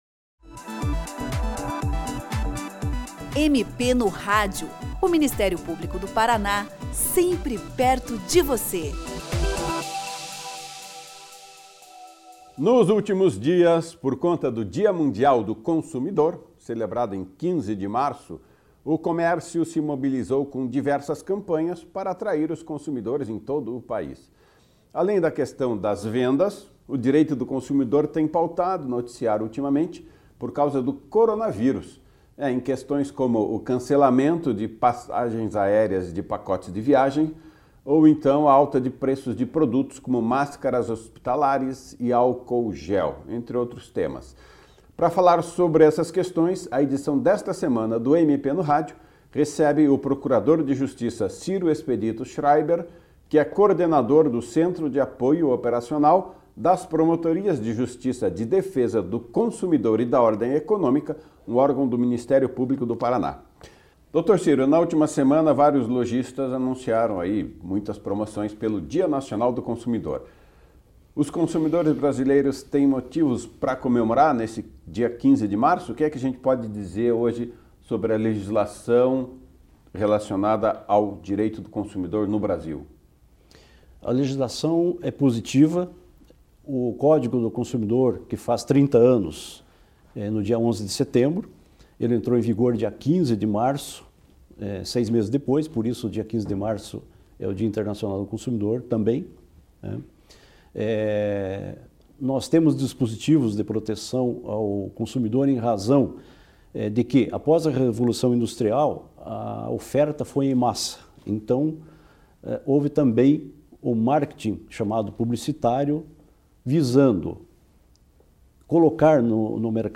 Por conta do Dia Mundial do Consumidor, celebrado recentemente, em 15 de março, o MP no Rádio traz nesta semana uma entrevista com o procurador de Justiça Ciro Expedito Scheraiber, coordenador do Centro de Apoio Operacional das Promotorias de Justiça de Defesa do Consumidor e da Ordem Econômica, do Ministério Público do Paraná.